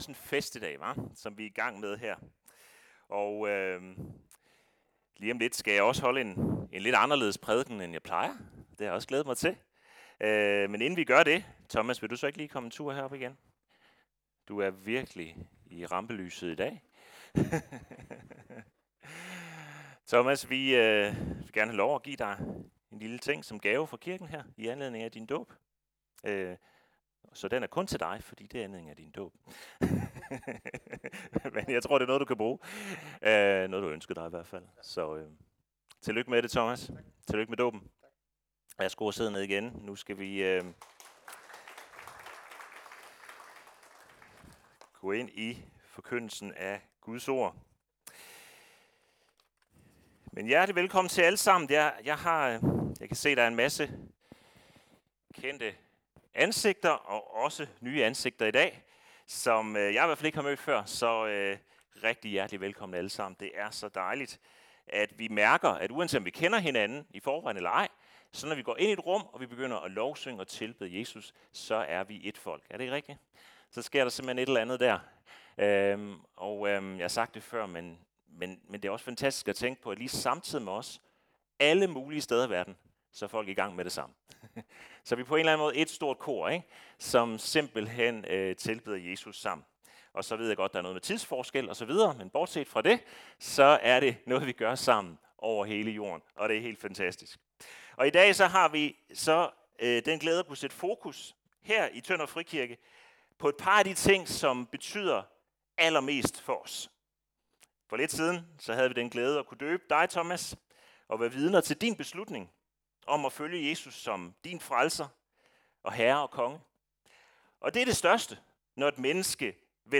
Prædikener fra Tønder Frikirke Service Type: Fest Gudstjeneste Topics: Vielsen en hellig pagt